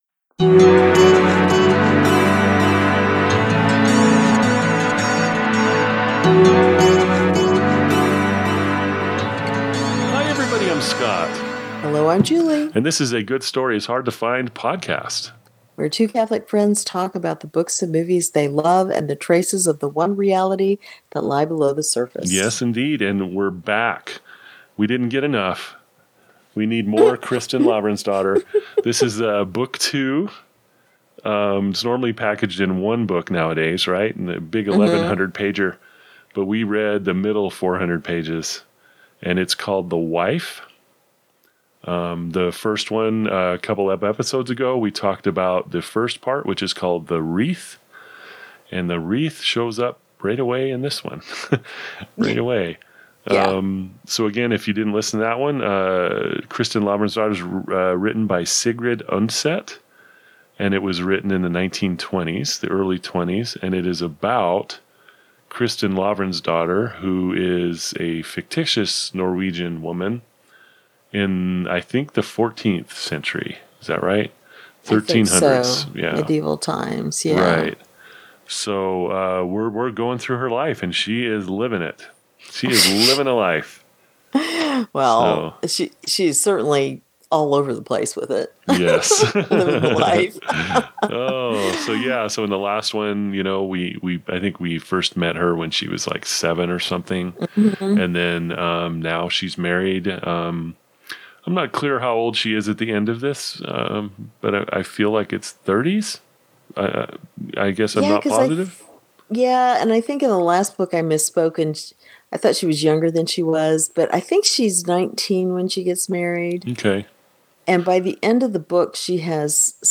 Two Catholics talking about books, movies and traces of "the One Reality" they find below the surface.